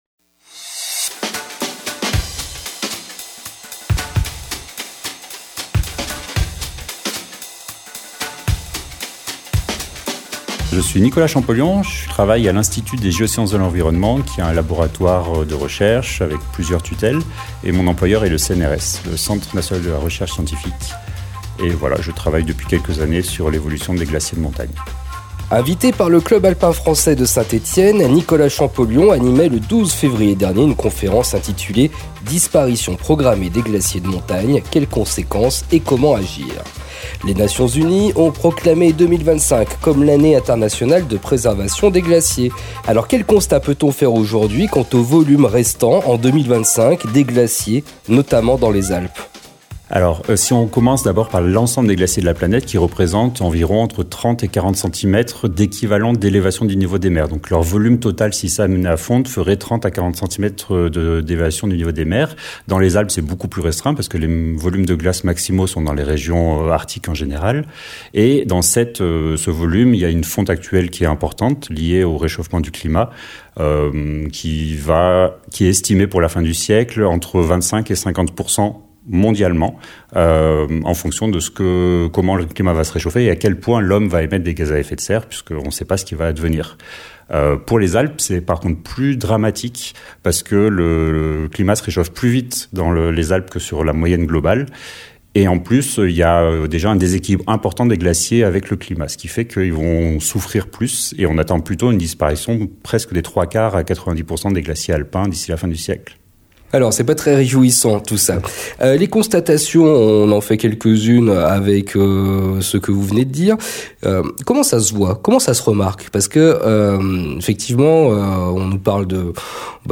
DISPARITION PROGRAMMÉE DES GLACIERS, entretien